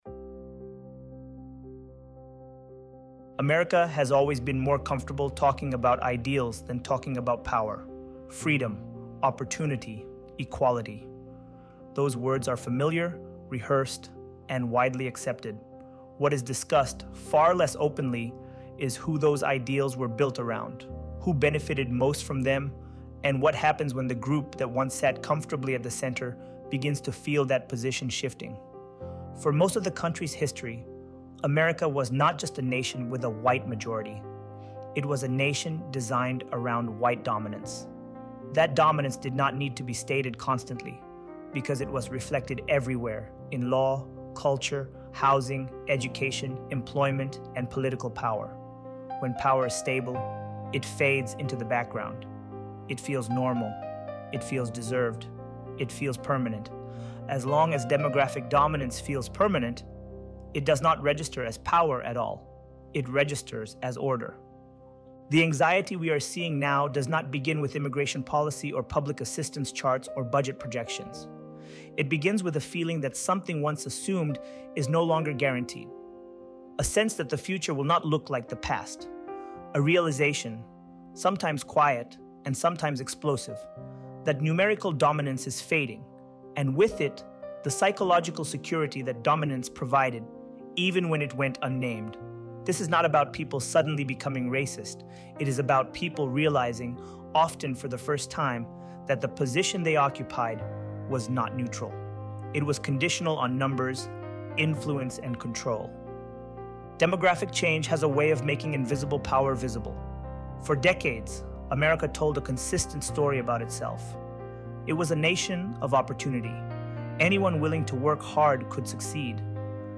ElevenLabs_How_America_Built_Immigration_Policy_Around_Demographic_Control.mp3